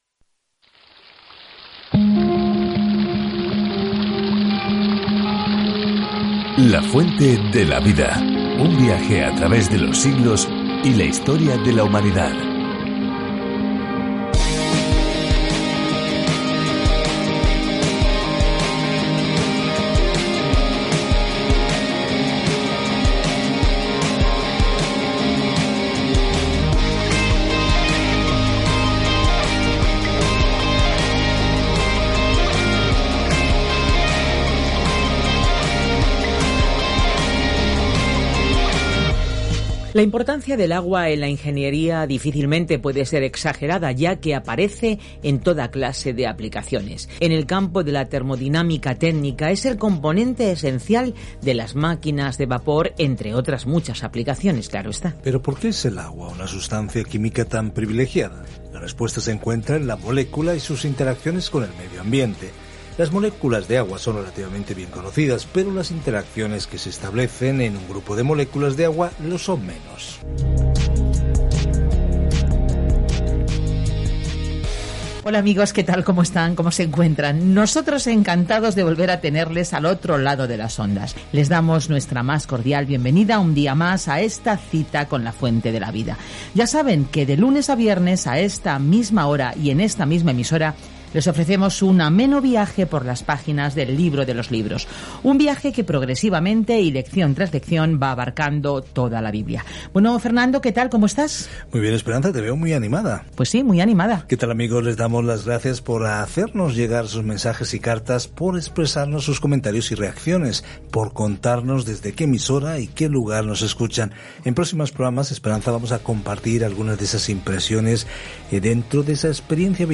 Scripture Hosea 5:15 Hosea 6:1-11 Day 9 Start this Plan Day 11 About this Plan Dios usó el doloroso matrimonio de Oseas como ilustración de cómo se siente cuando su pueblo le es infiel, y aun así se compromete a amarlos. Viaja diariamente a través de Oseas mientras escuchas el estudio de audio y lees versículos seleccionados de la palabra de Dios.